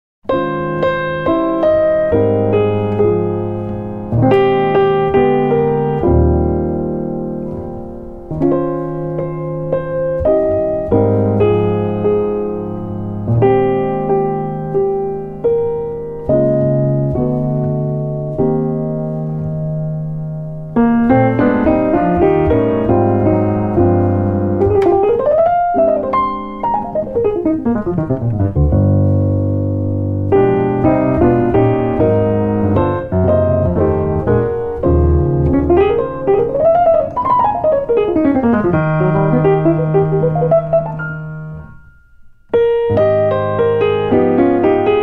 piano
bass
drums